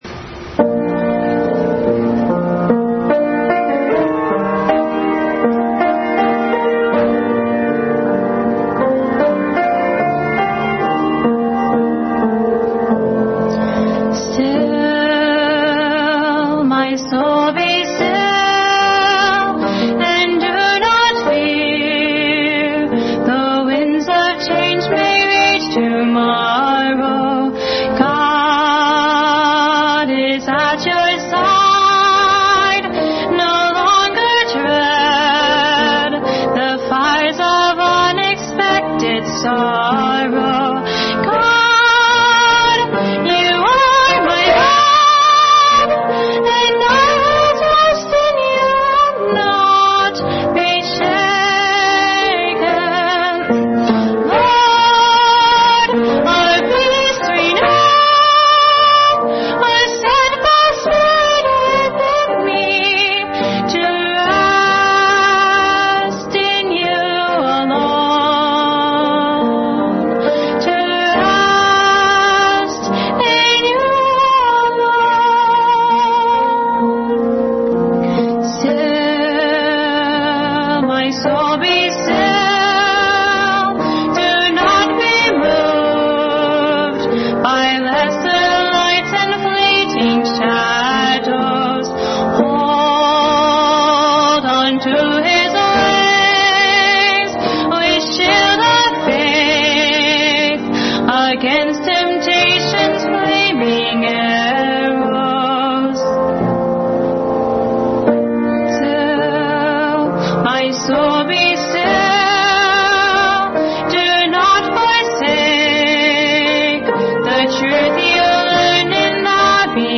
Special Music – “Still My Soul Be Still”